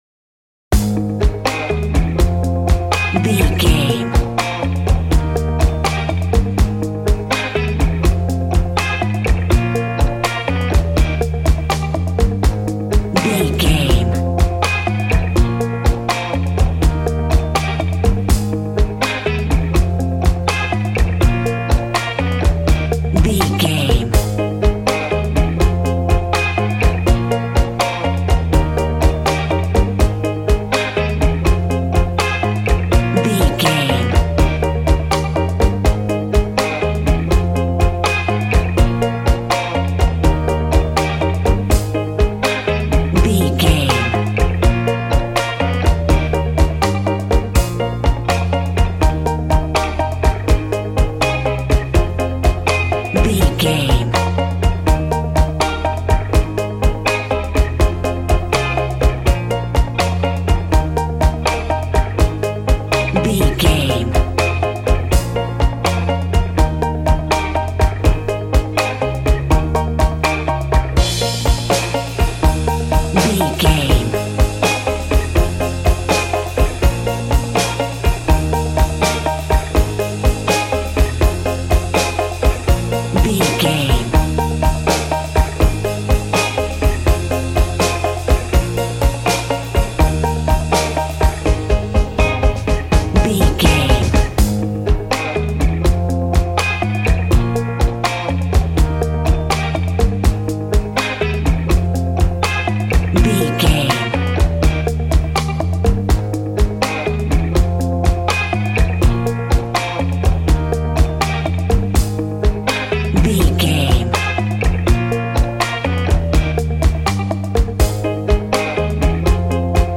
Ionian/Major
A♭
cheerful/happy
double bass
drums
piano